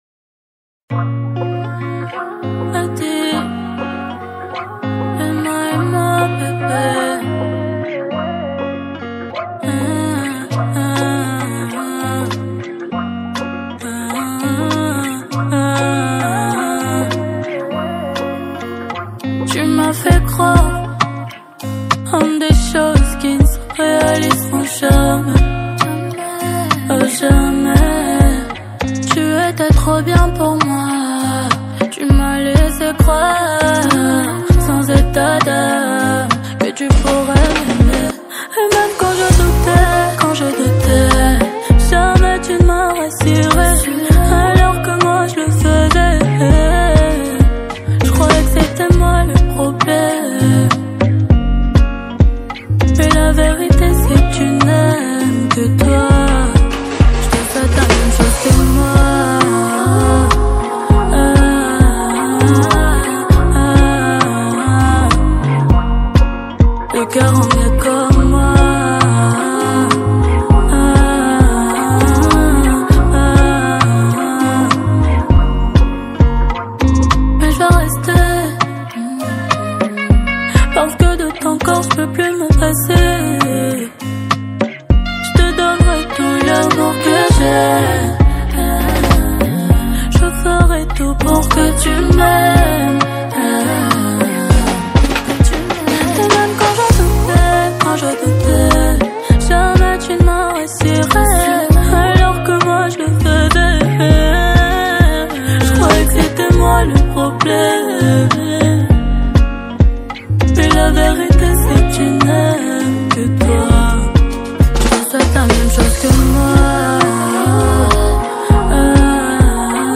| Afro zouk